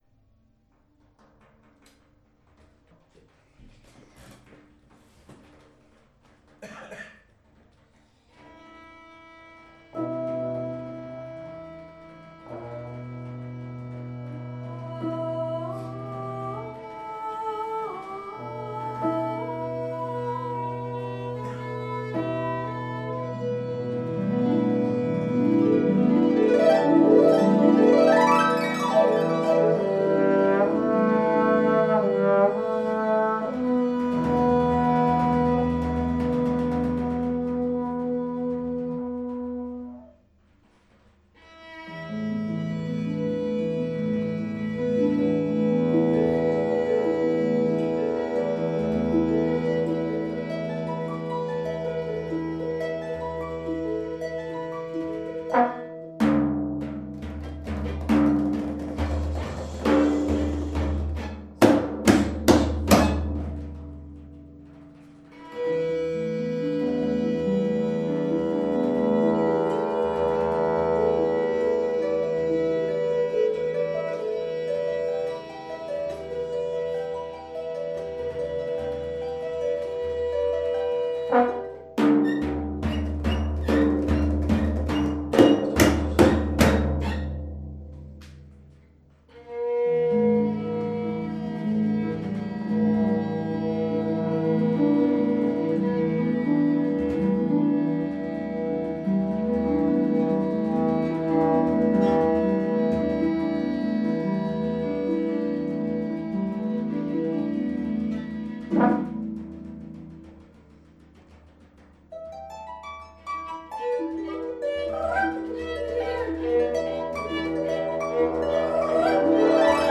(prise de son en direct, pendant des travaux en groupes)